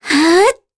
Talisha-Vox_Casting6_kr.wav